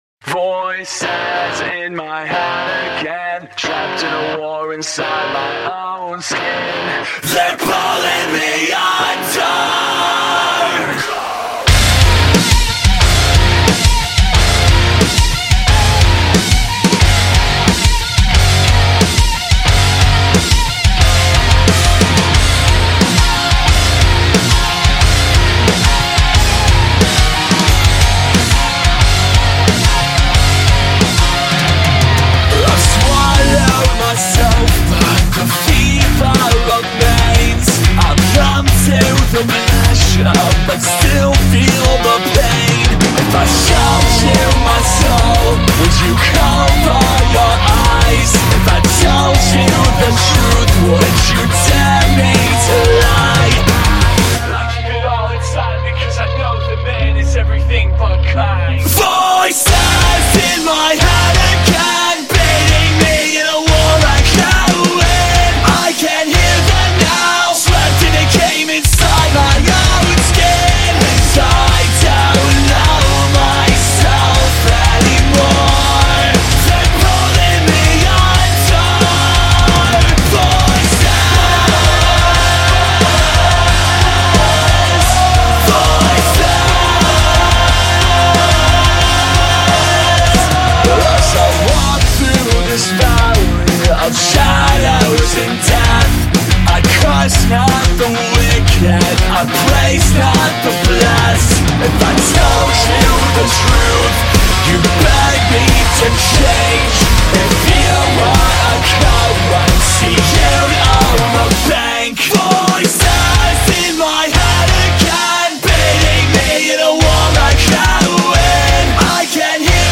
Genre: industrial metal
Gothic Metal